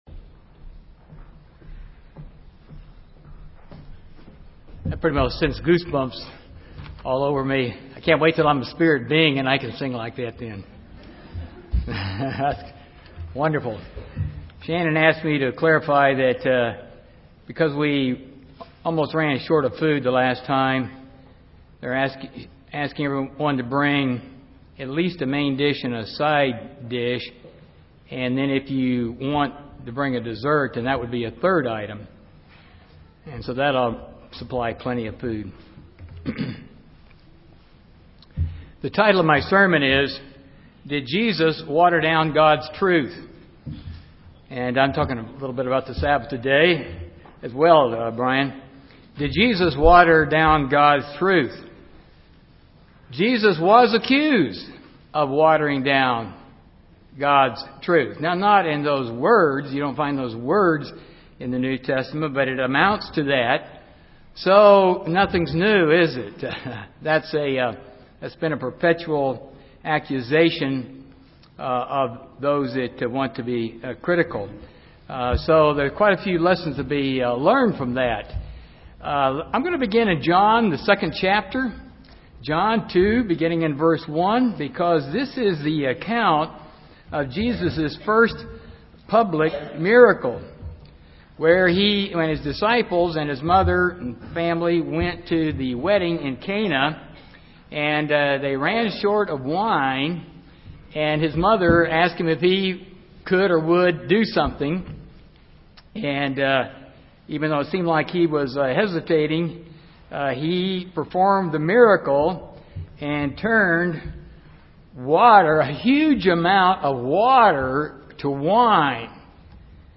Given in Dallas, TX
UCG Sermon Studying the bible?